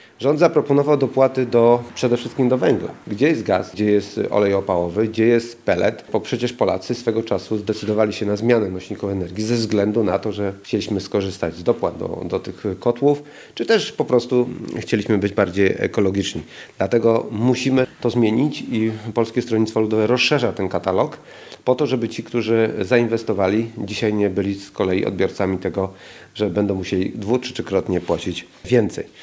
Uwolnienie energii z OZE, dopłata do węgla, oleju opałowego, pelletu, ekogroszku czy też likwidacja podatku Belki – to tylko niektóre z propozycji Polskiego Stronnictwa Ludowego przedstawione przez posła Jarosława Rzepę podczas dzisiejszej konferencji prasowej w Szczecinie.